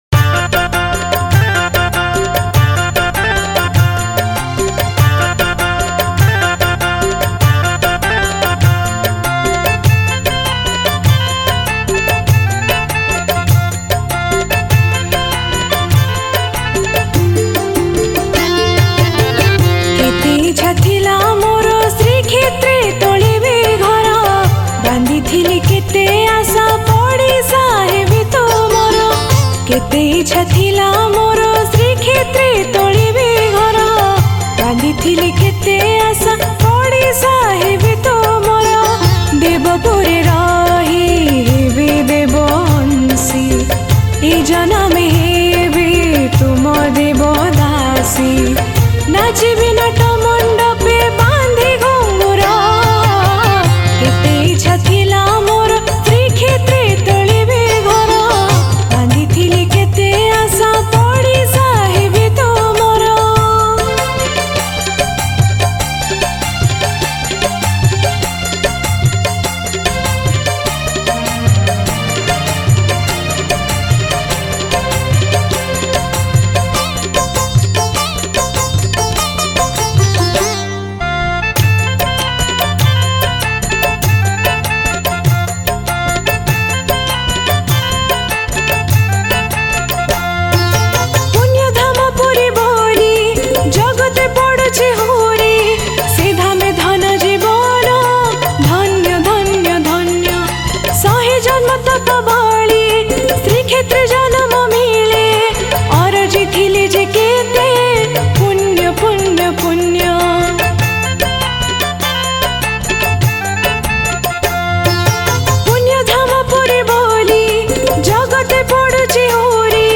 Category: Odia Bhakti Hits Songs